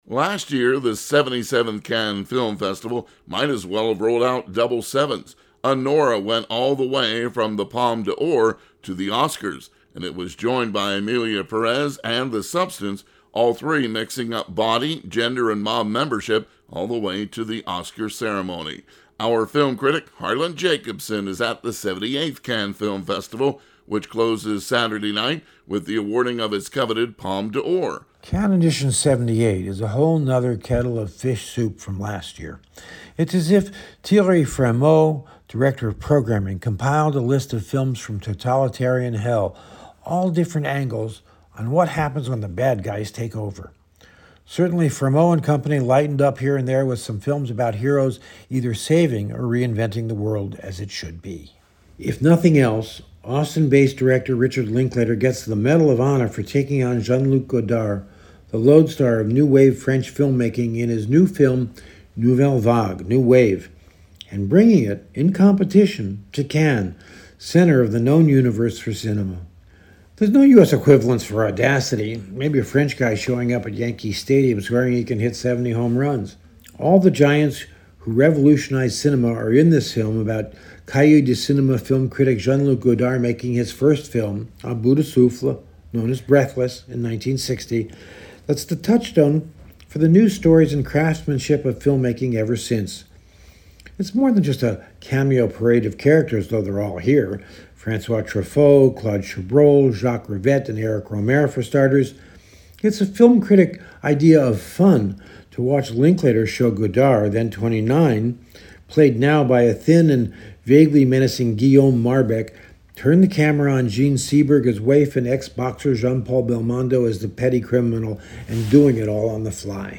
Broadcast on WBGO, May 25, 2025